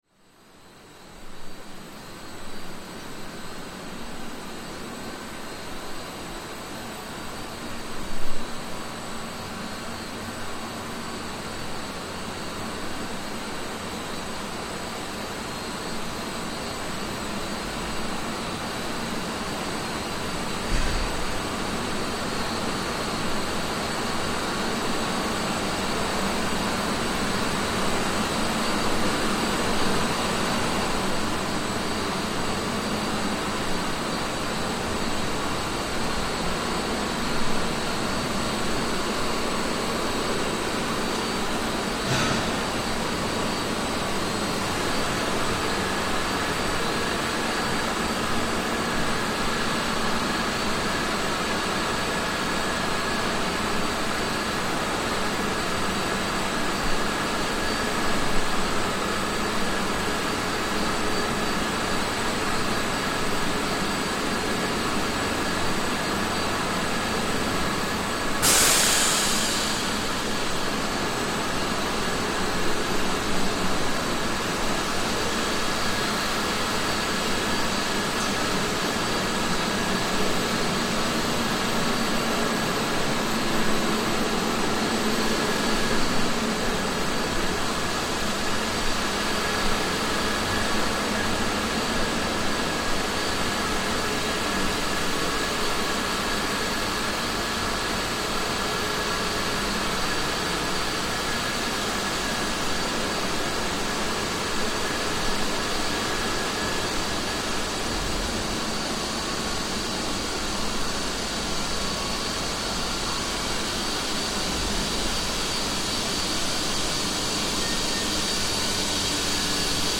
Beer-making inside the Pohjala brewery
On a visit to the Pohjala artisan brewery in Tallinn, Estonia, we were invited behind the scenes into the brewery itself to record the sounds of beer being made. This soundscape records the beer-making process, with loud industrial noises, drones and hisses coming from large brewing tanks and machinery.